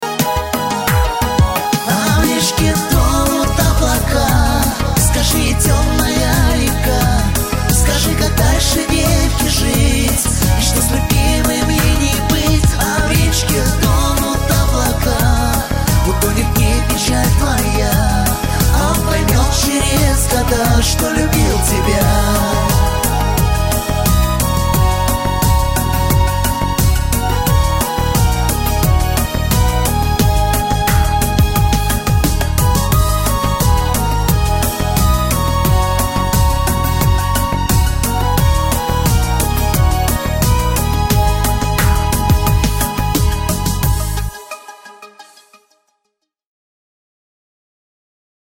• Качество: 320, Stereo
мужской вокал
грустные
русский шансон